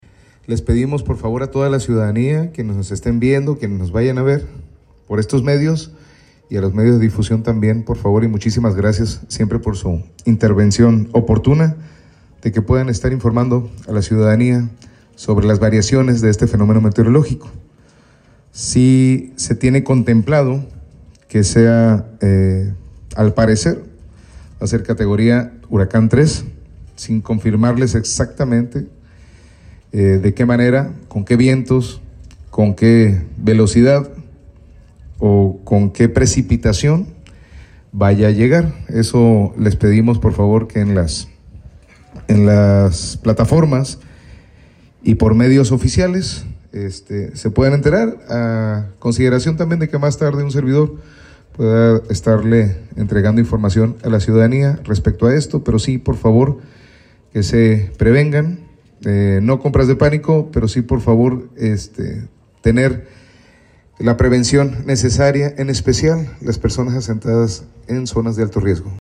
Los Cabos, Baja California Sur.- El presidente municipal Christian Agúndez Gómez, dirigió un mensaje a la población de Los Cabos, para reforzar las medidas de prevención en sus hogares y centros de trabajo, ante las condiciones meteorológicas derivadas del huracán “Priscilla”, que se aproxima a las costas del sur del estado.
Presidente Municipal Christian Agúndez Gómez